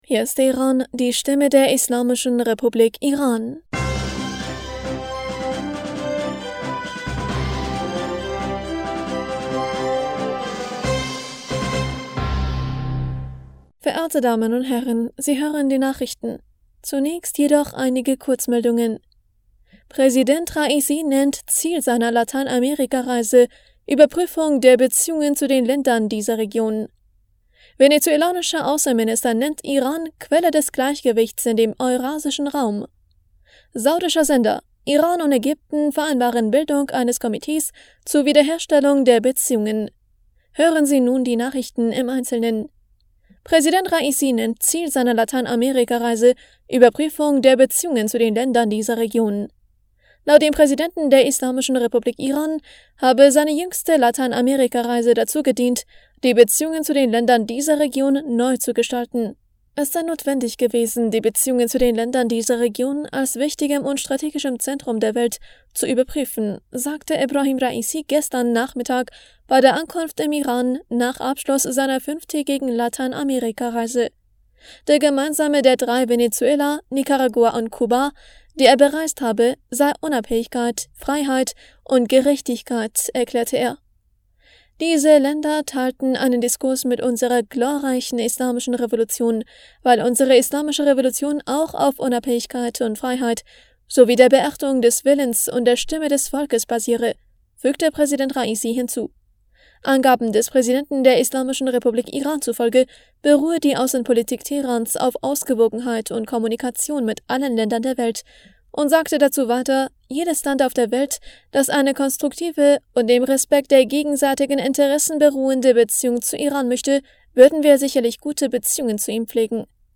Nachrichten vom 17. Juni 2023